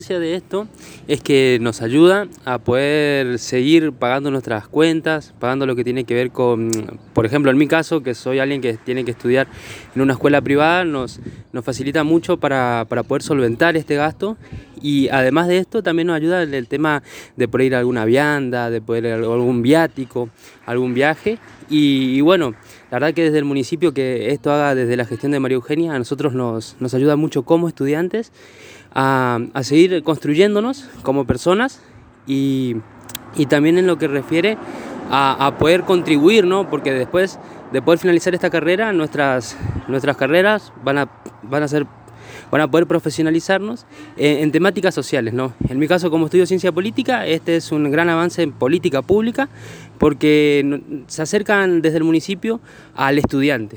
En diálogo exclusivo con la ANG